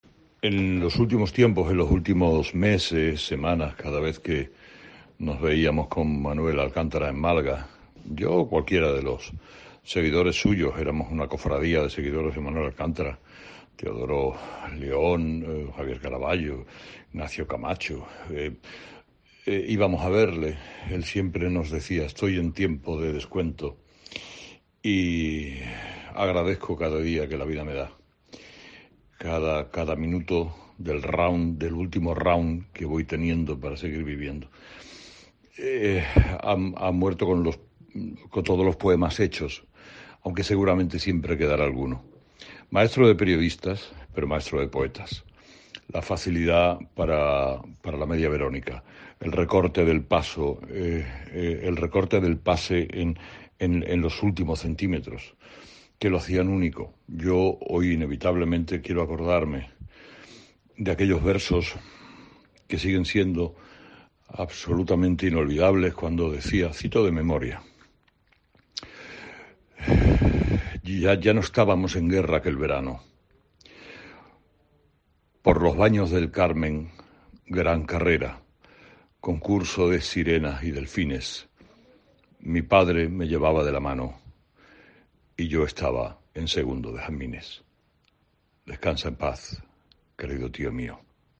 Herrera recita uno de los versos de Manuel Alcántara en su despedida